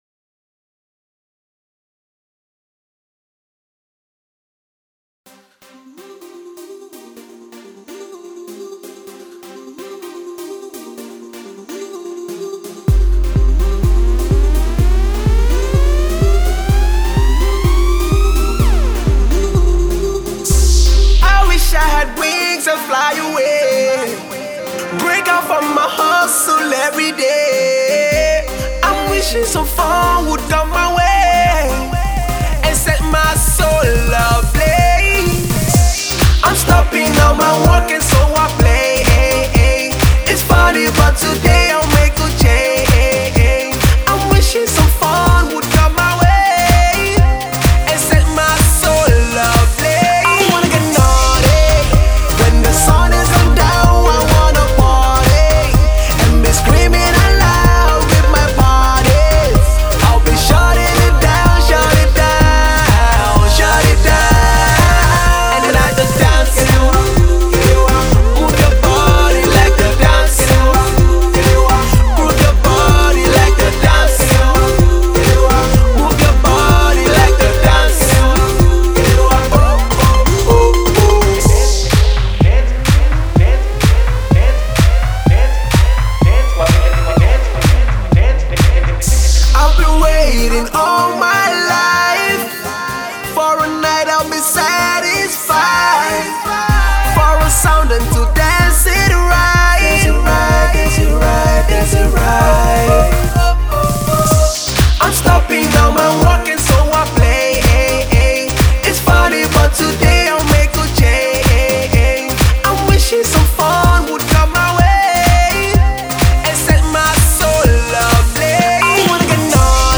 beat heavy Afro-Pop/Dance tune for the clubs